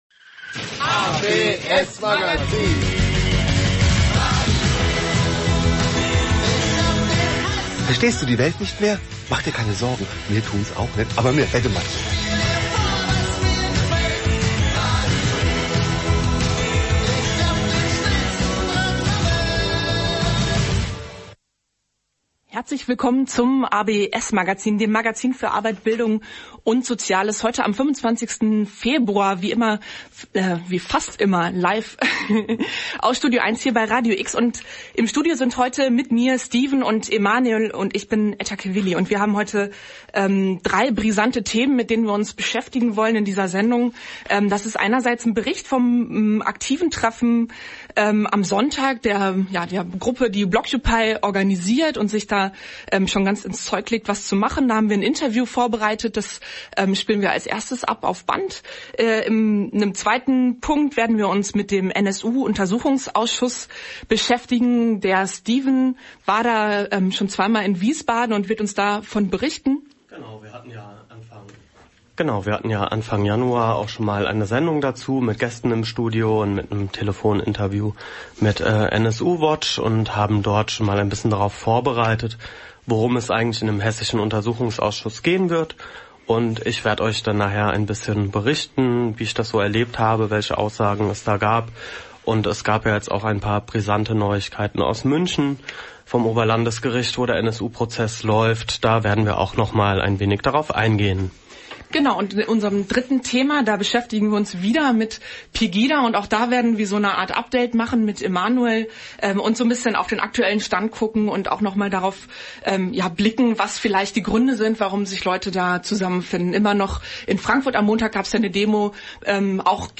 TTIP, CETA und TISA / Der gläserne Bürger / Behördenwillkür In unserer heutigen Sendung hatten wir vier Gäste. Als Schwerpunktthema behandelten wir internationale Handelsabkommen und ihre Folgen, um im letzten Drittel über Datensicherheit und den gläsernen Bürger zu sprechen. Am Ende wurde eine neue Bürgerinitiative gegen Behördenwillkür vorgestellt.